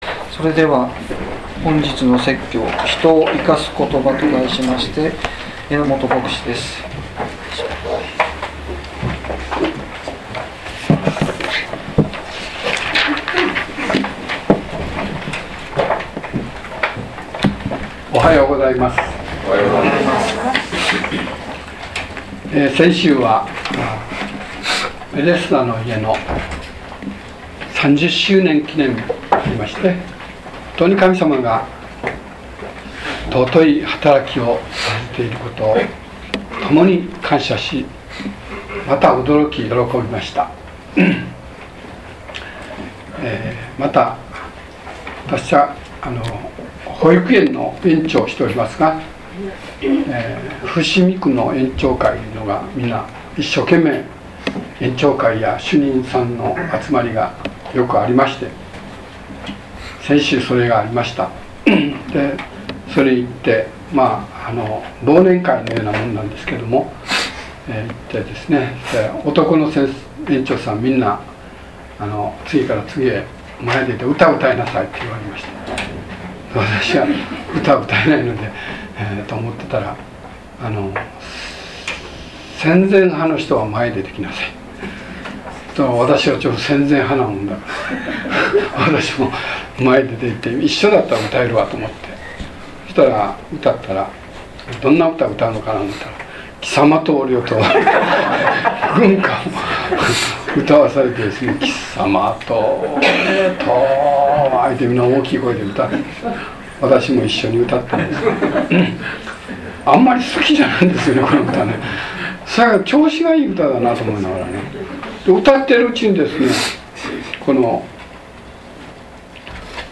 説教要旨 2012年12月16日 人を生かす言葉 | 日本基督教団 世光教会 京都市伏見区